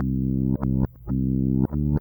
bassguitar1 reversed 001.wav